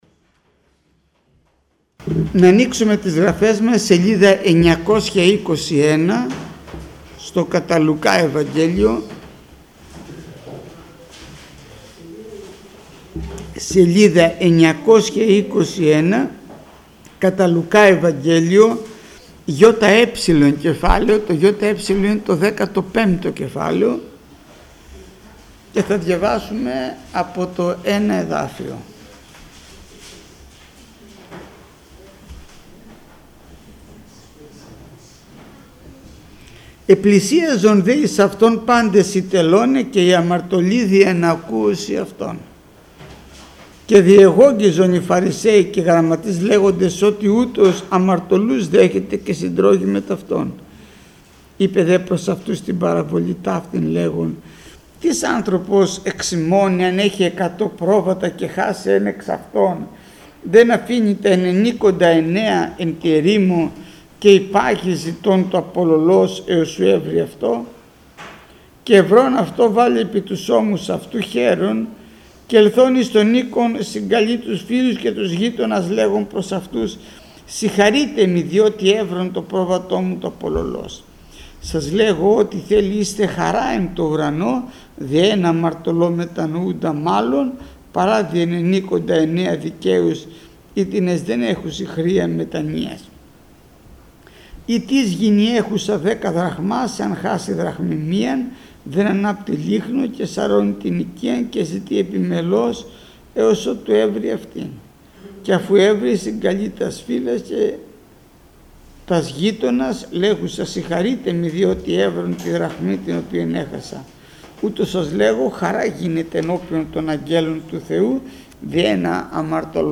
ΜΑΘΗΜΑΤΑ ΚΥΡΙΑΚΗΣ